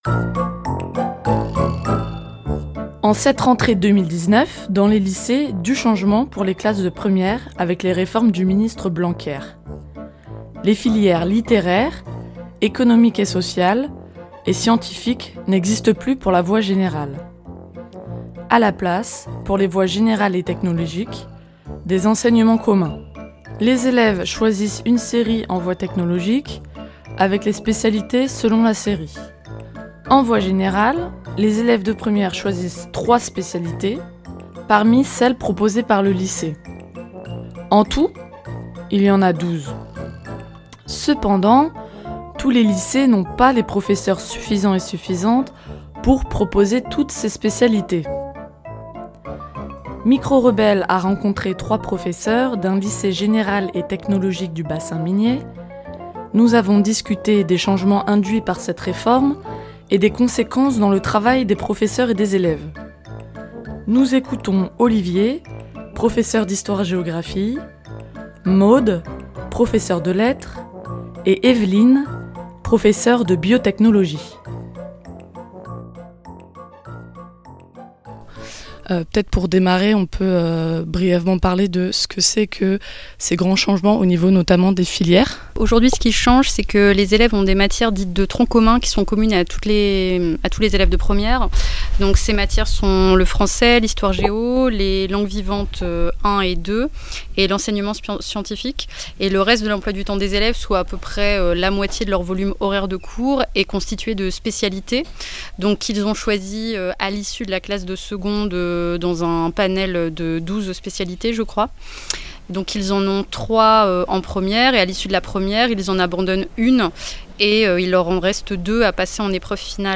Cette rentrée 2019 dans les lycées s’est faite sous le signe du changement avec la loi Blanquer. Micros-rebelles a rencontré 3 professeurs d’un lycée technologique et général du Pas-de-Calais pour parler des conséquences de cette réforme des lycée et du bac sur leur travail et sur les enseignements dispensés.